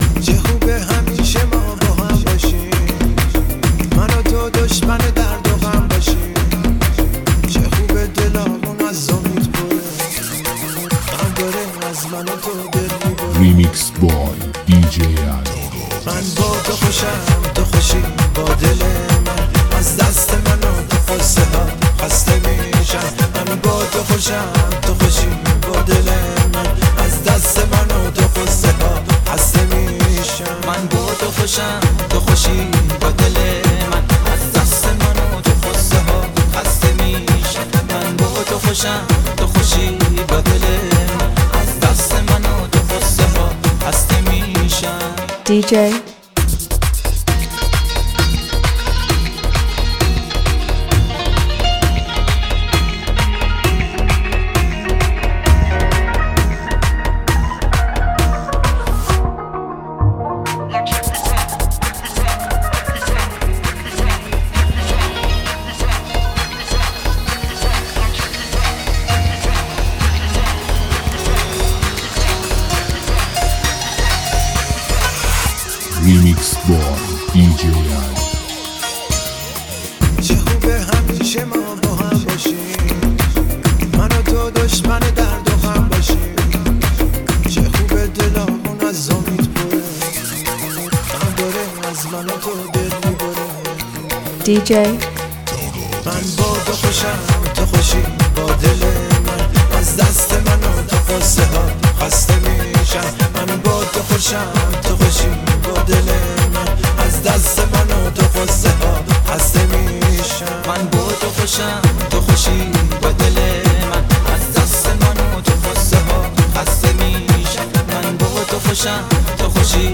لذت بردن از موسیقی پرانرژی و بیس قوی، هم‌اکنون در سایت ما.